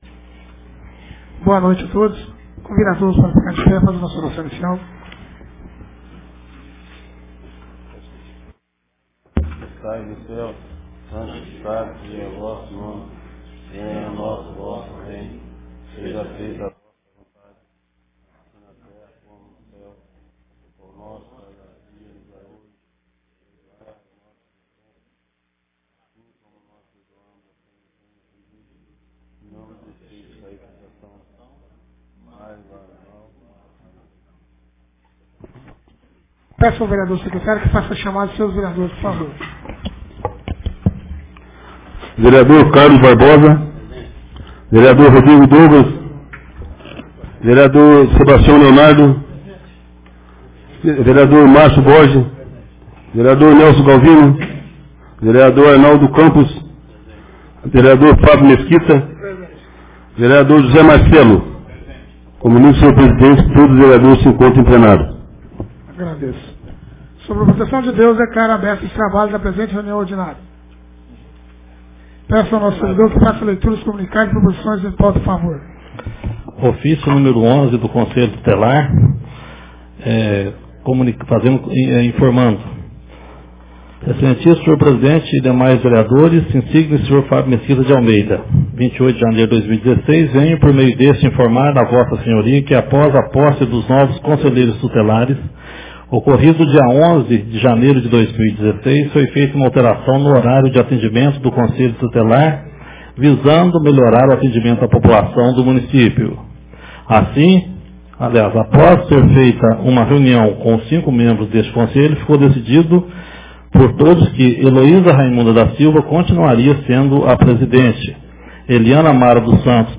Ata da 2ª Reunião Ordinária de 2016